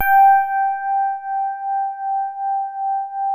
FINE SOFT G4.wav